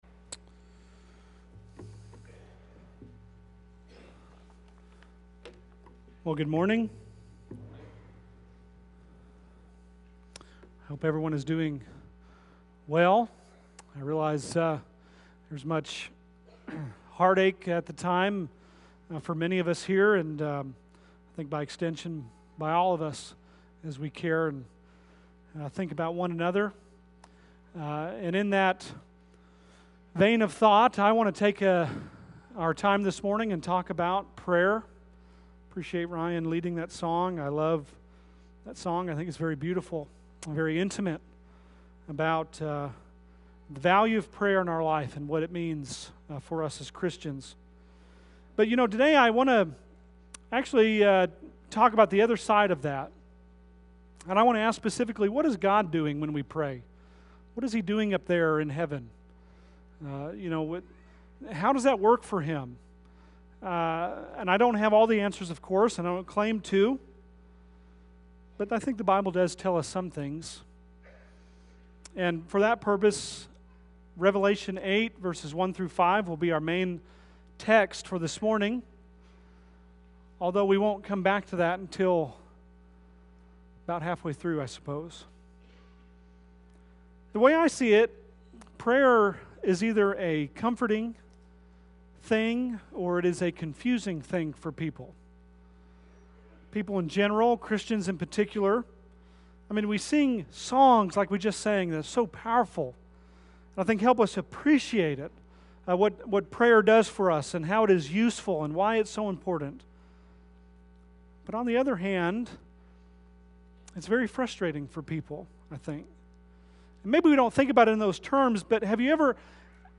Bible Text: Revelation 8:1-5 | Preacher